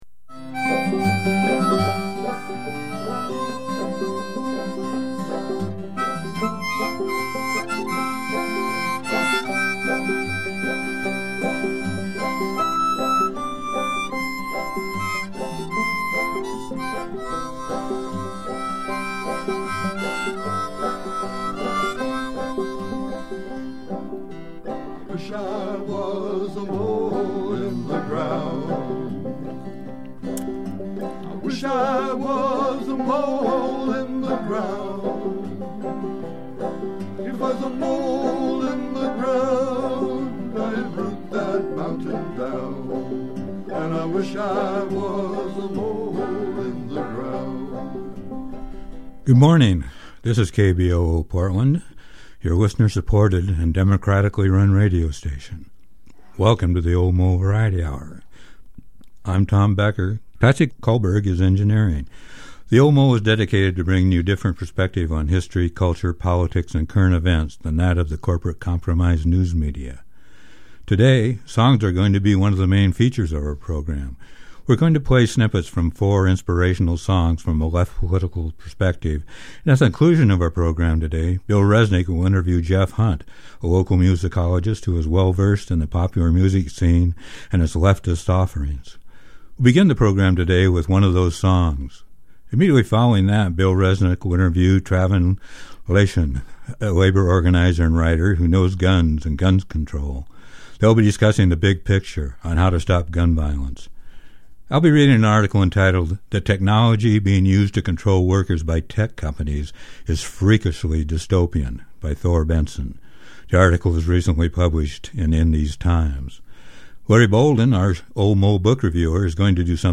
The whole show includes music segments referred to in the last piece of the show.